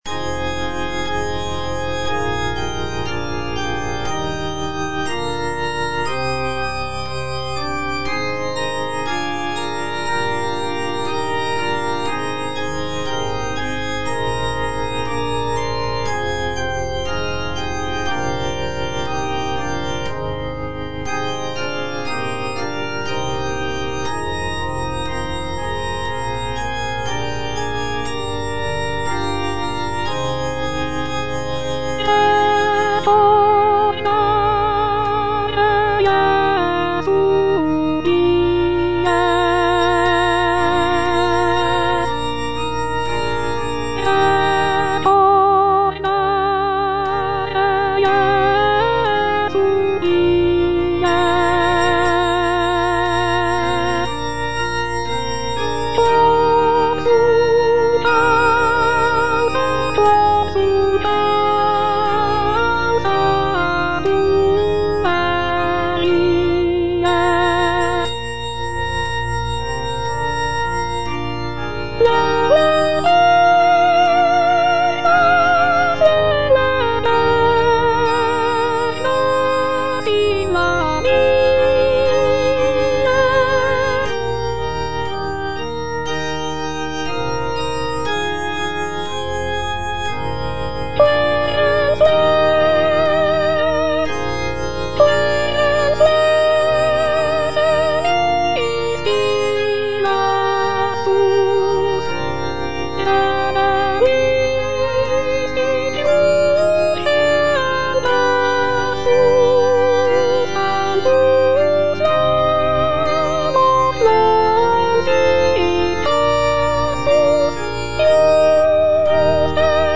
Soprano (Voice with metronome) Ads stop